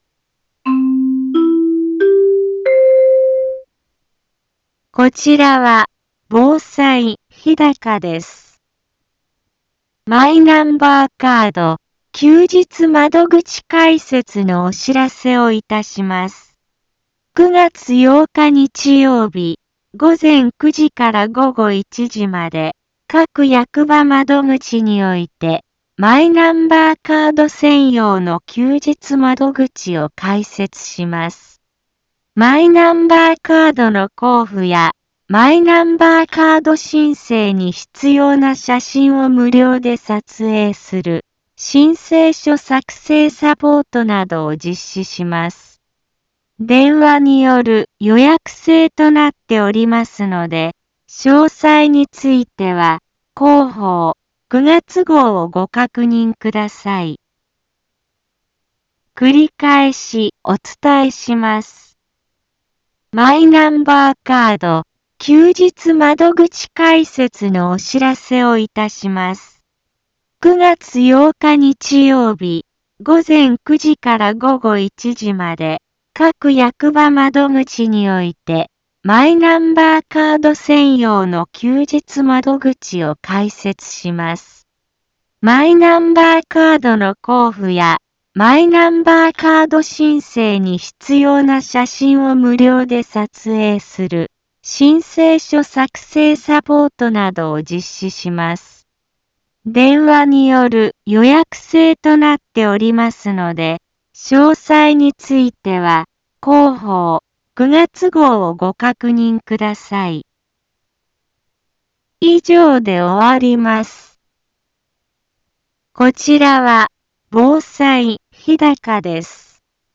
一般放送情報
Back Home 一般放送情報 音声放送 再生 一般放送情報 登録日時：2024-08-30 15:04:22 タイトル：マイナンバーカード休日窓口開設のお知らせ インフォメーション： マイナンバーカード休日窓口開設のお知らせをいたします。 9月8日日曜日、午前9時から午後1時まで、各役場窓口において、マイナンバーカード専用の休日窓口を開設します。